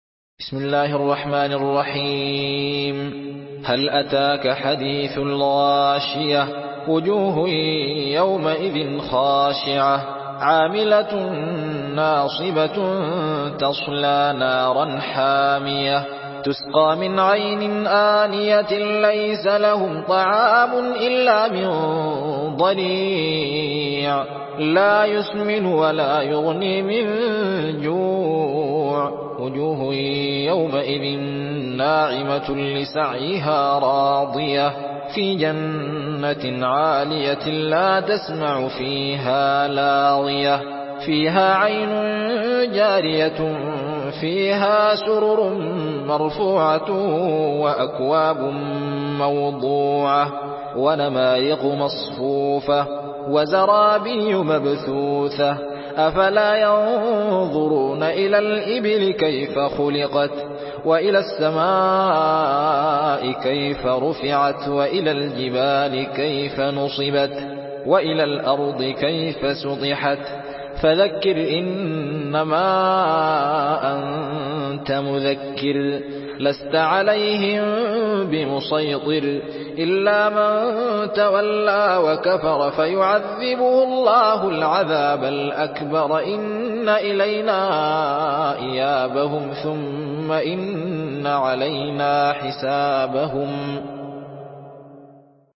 سورة الغاشية MP3 بصوت الزين محمد أحمد برواية حفص
مرتل